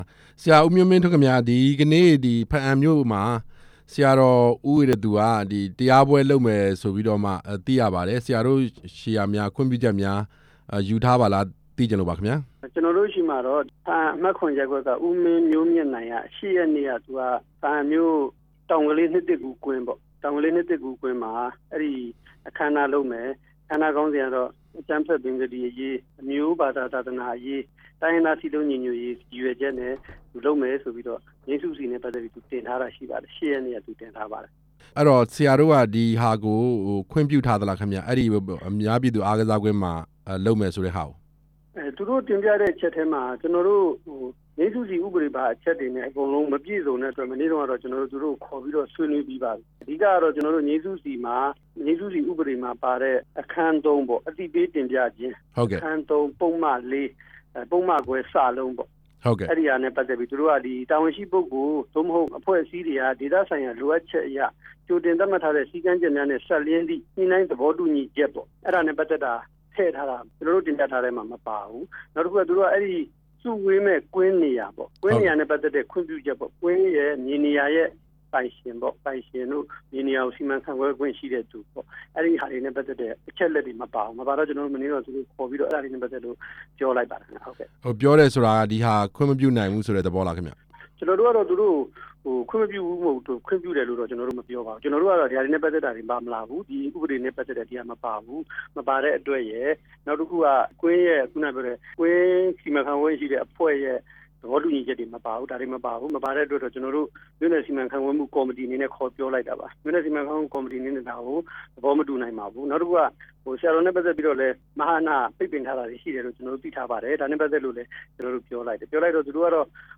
မြို့နယ်အုပ်ချုပ်ရေးမှူးနဲ့ ဆက်သွယ်မေးမြန်းချက်ကို  နားဆင်နိုင်ပါတယ်။